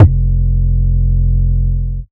Index of /Antidote Advent/Drums - 808 Kicks
808 Kicks 04 F.wav